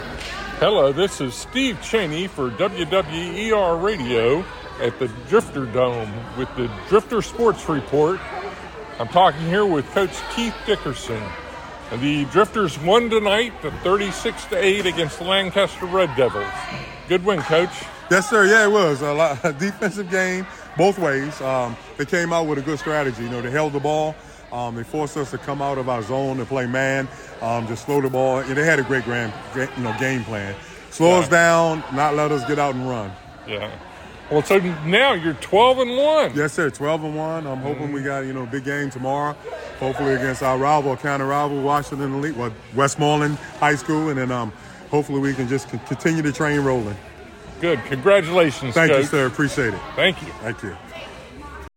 Drifters Sports Report